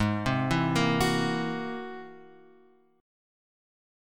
G# Augmented 9th
G#+9 chord {4 3 2 3 x 2} chord